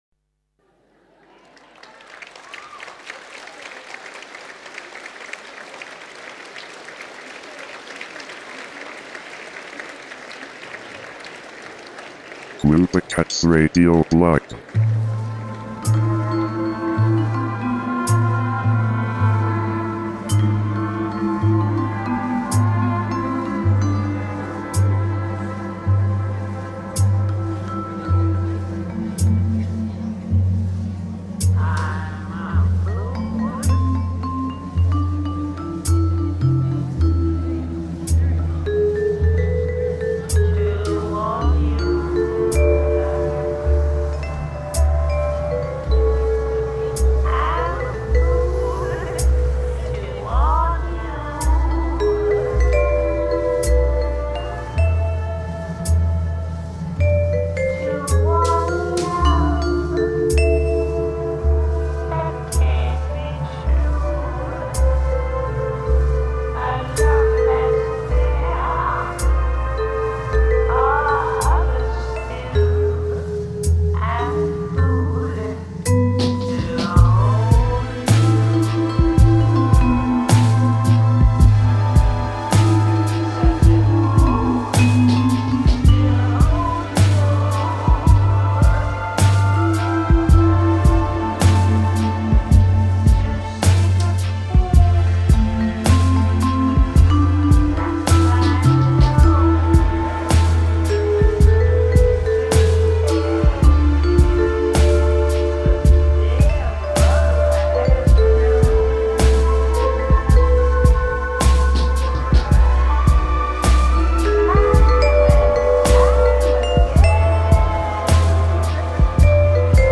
a lounge mix